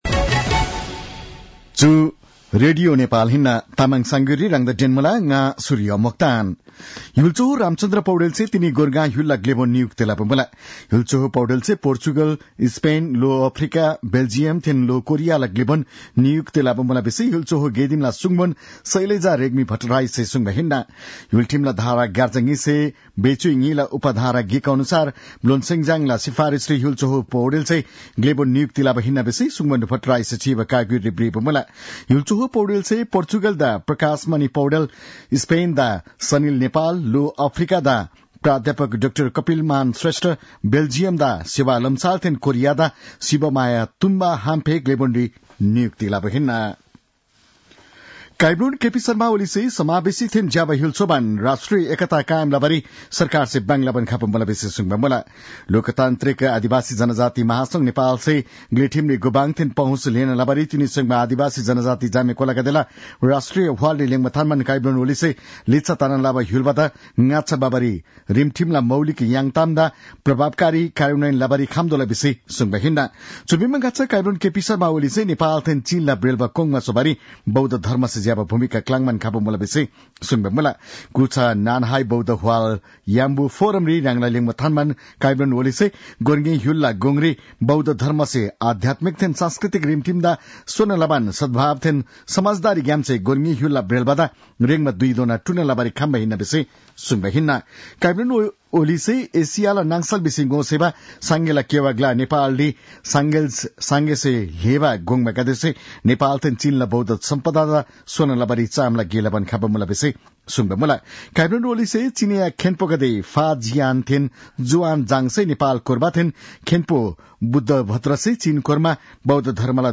तामाङ भाषाको समाचार : २९ मंसिर , २०८१
Tamang-news-8-28.mp3